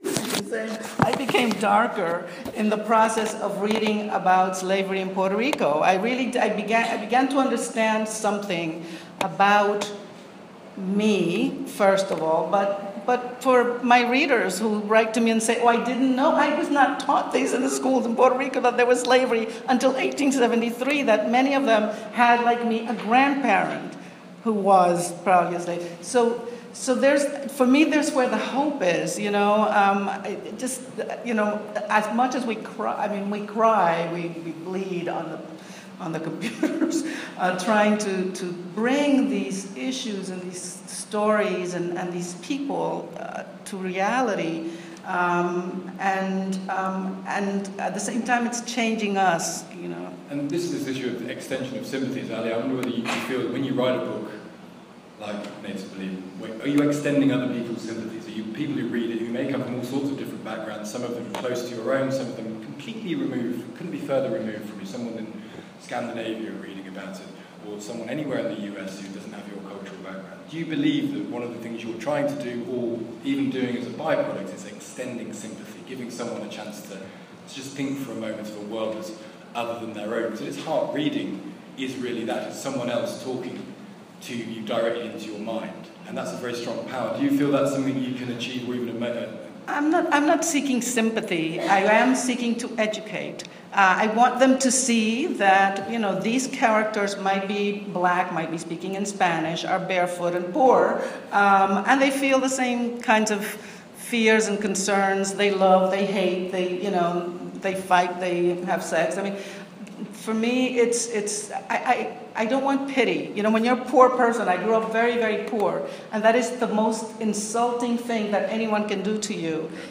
Brooklyn Book Festival (Sunday, Sept. 18, 2016)
Brooklyn+Book+Fest+2016.m4a